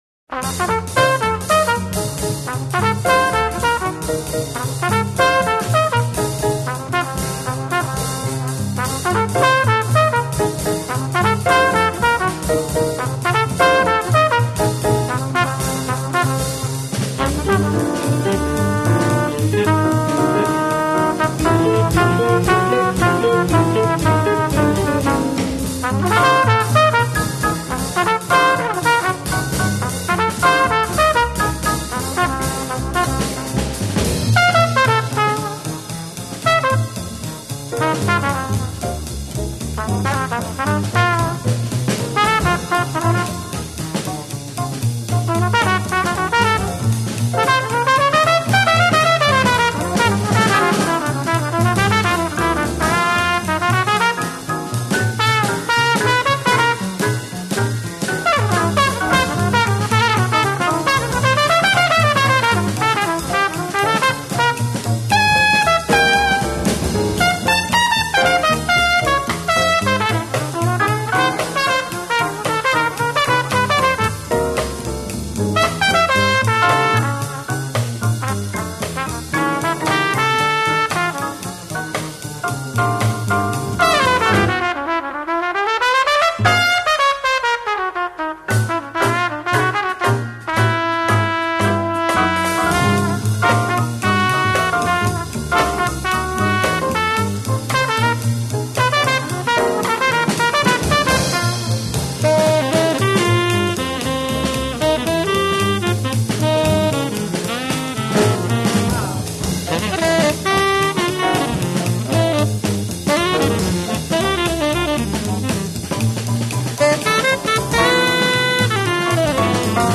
ten.sax,flute